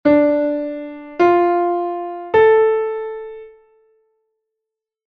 Acorde perfecto menor
RE-FA-LA